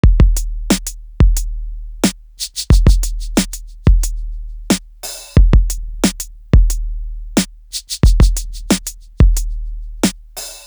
Crushed Linen Drum.wav